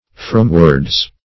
Search Result for " fromwards" : The Collaborative International Dictionary of English v.0.48: Fromward \From"ward\, Fromwards \From"wards\, prep.